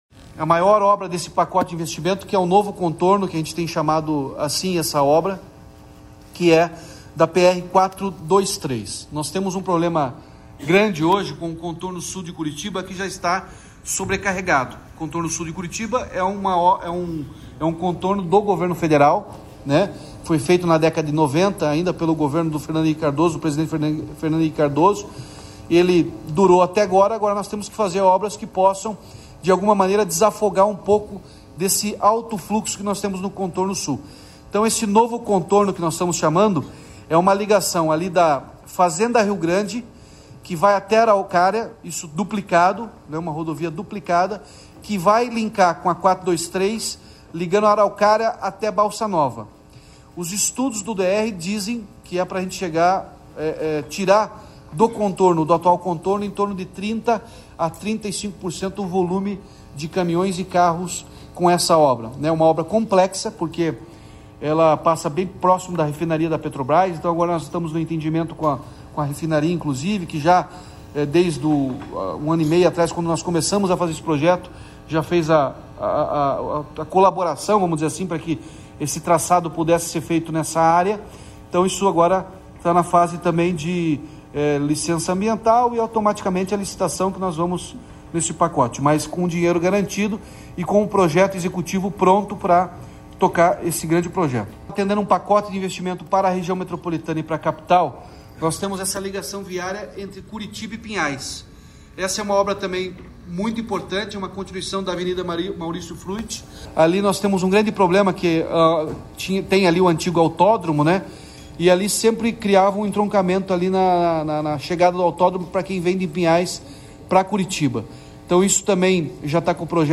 Sonora do governador Ratinho Junior sobre os investimentos de infraestrutura para a RMC